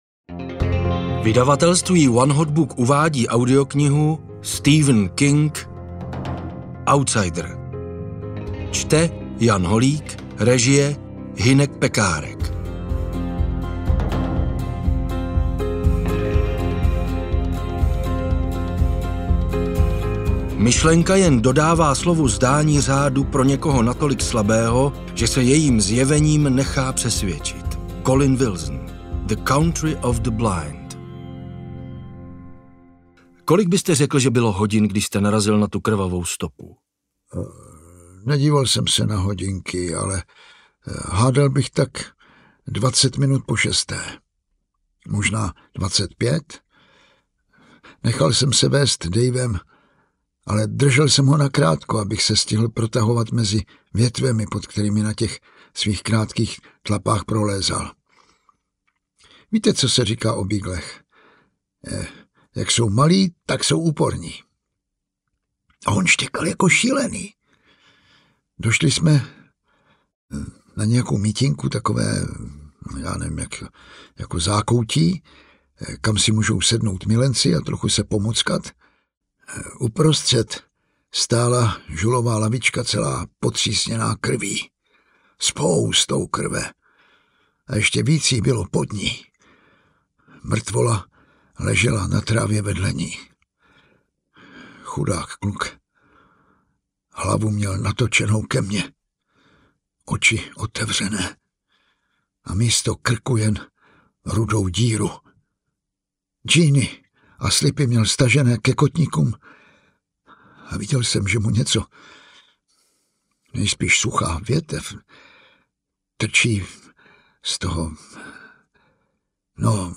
Outsider audiokniha
Ukázka z knihy